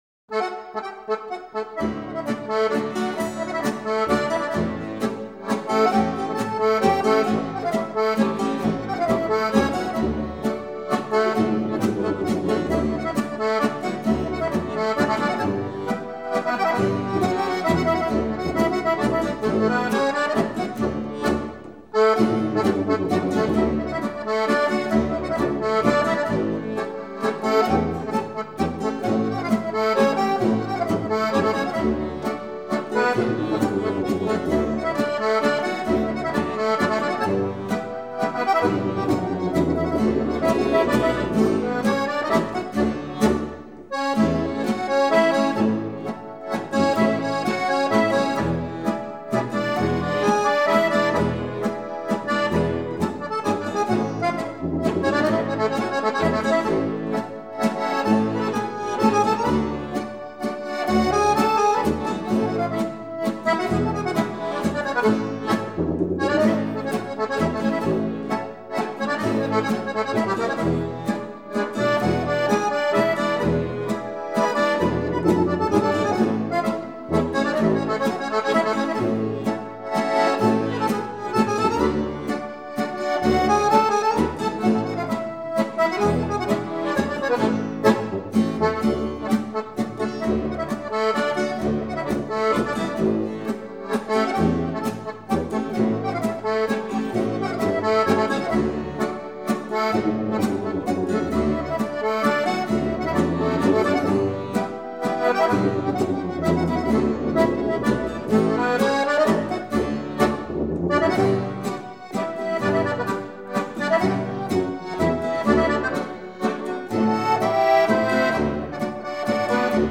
Ticino: Genuine Folk Music from Southern Switzerland
Trio Malcantonese